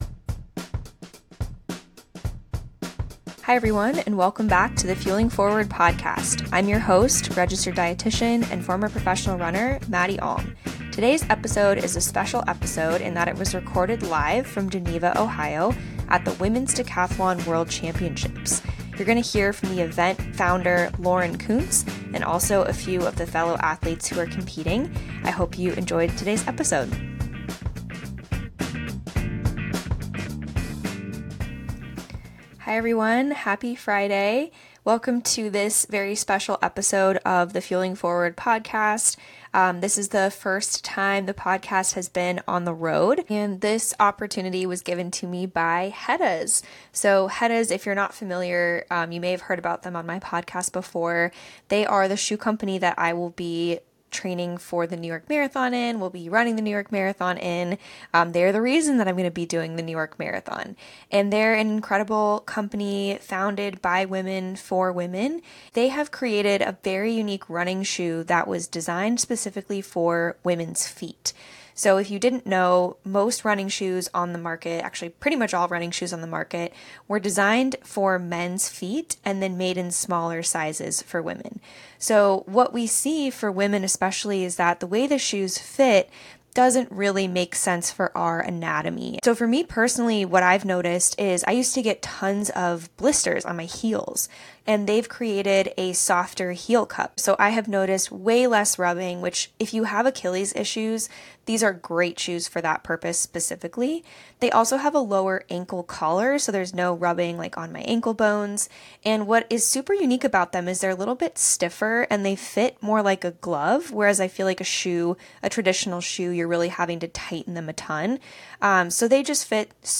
This is the first ever live recorded episode of The Fueling Forward Podcast!